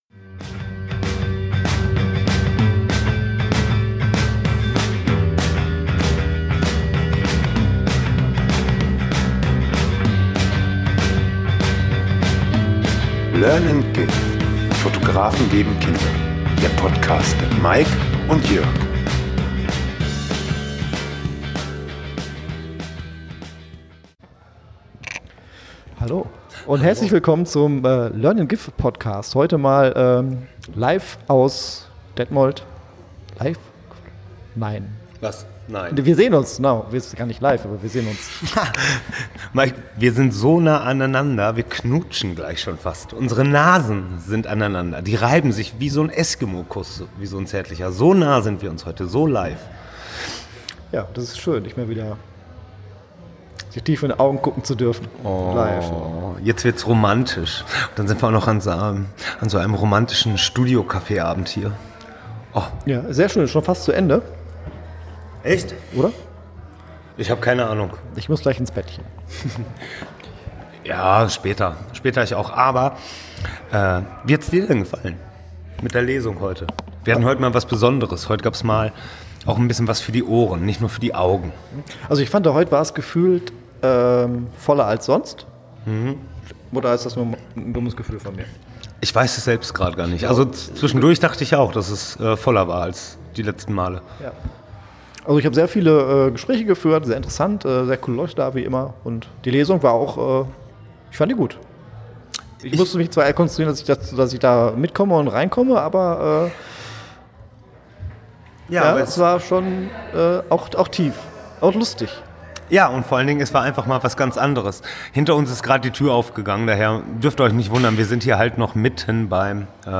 Interviews über Kreativität ~ Learn and Give
Wir waren mit dem Mikrofone unterwegs und haben mit einem Maler, einem Musiker, einer Fotografin, einem Handwerker und einer Weddingplanerin über Kreativität gesprochen.